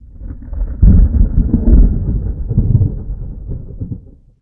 thunder5.ogg